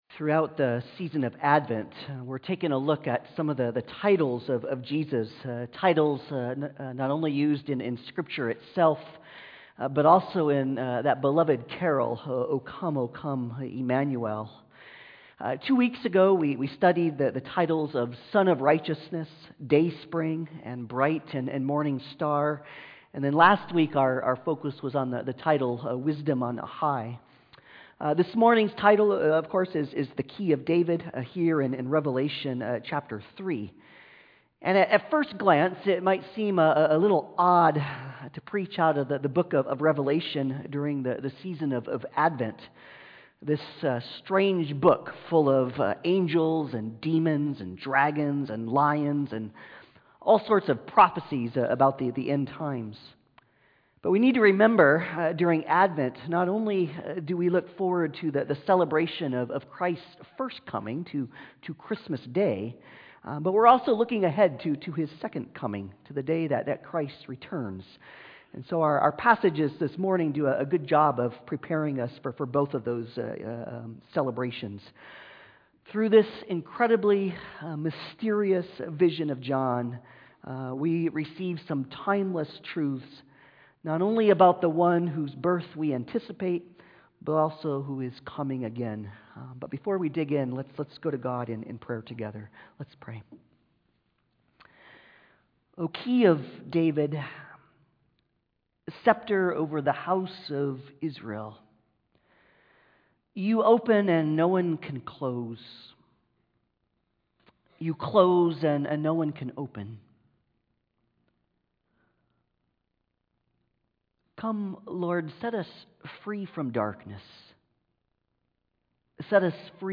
2023 Passage: Revelation 1:9-20, Revelation 3:7-13 Service Type: Sunday Service